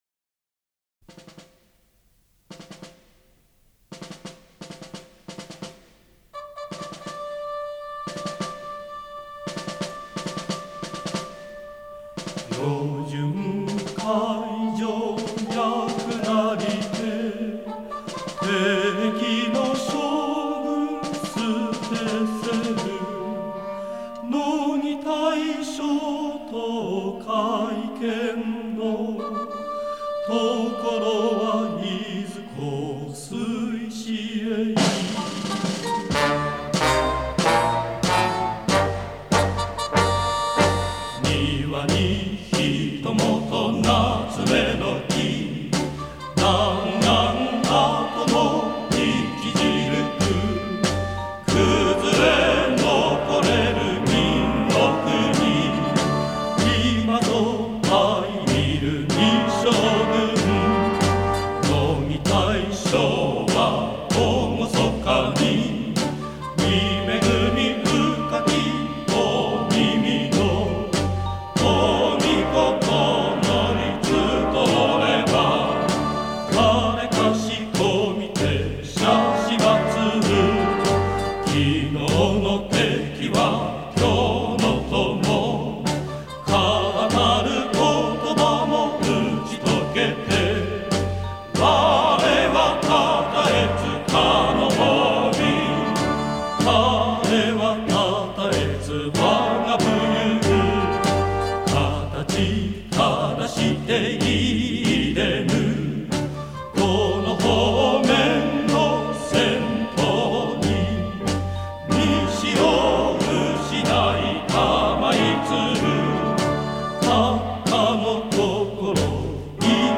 У японцев довольно красивая музыка (марши) времен войны.